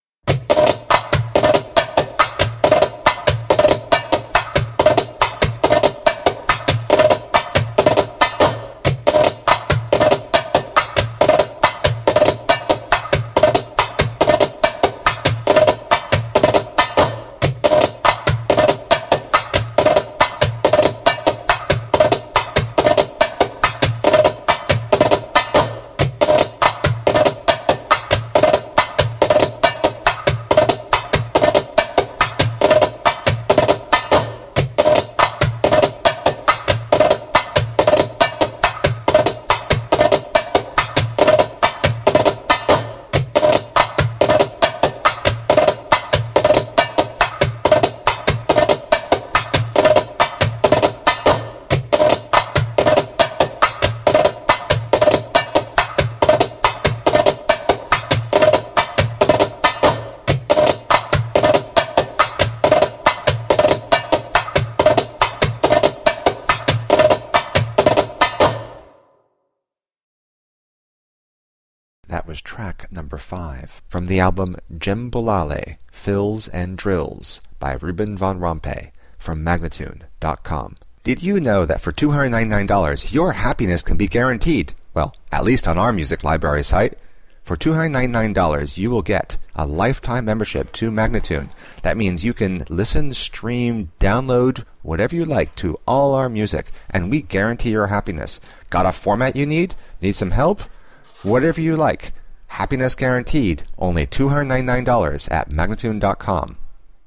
Passionate eastern percussion.